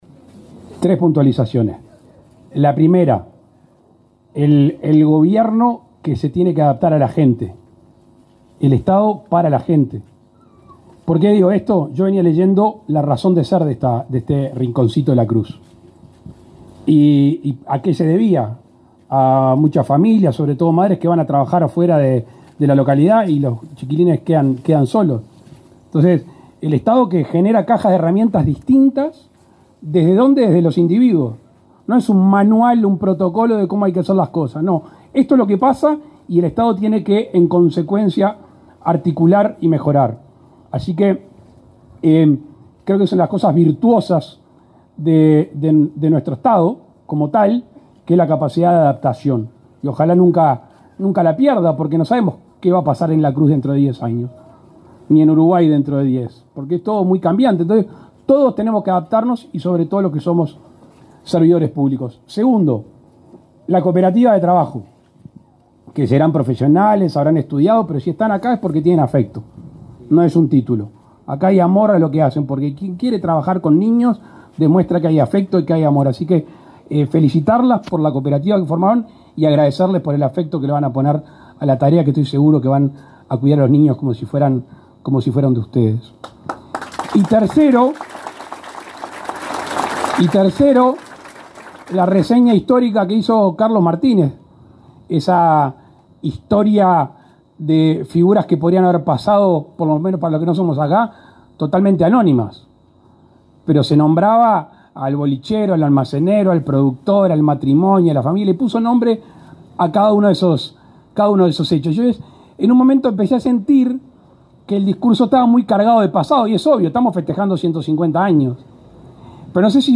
Palabras del presidente Luis Lacalle Pou
Palabras del presidente Luis Lacalle Pou 24/10/2024 Compartir Facebook X Copiar enlace WhatsApp LinkedIn Este jueves 24, el presidente de la República, Luis Lacalle Pou, participó en la inauguración de la casa comunitaria de cuidados del Instituto del Niño y el Adolescente del Uruguay (INAU) Rinconcito de La Cruz, en el departamento de Florida.